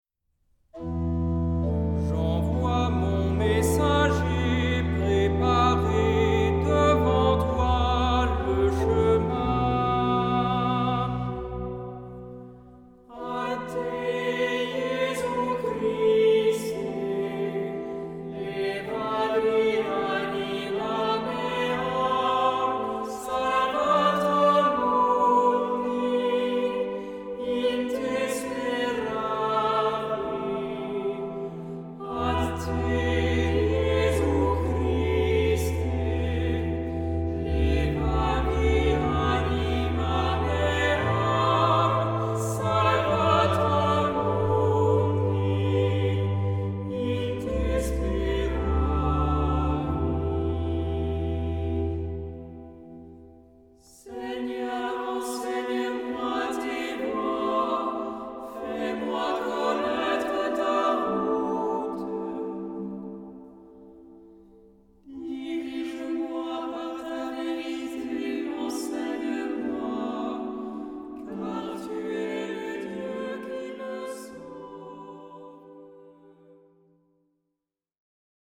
SAH O SATB (4 voces Coro mixto) ; Partitura general.
Salmodia.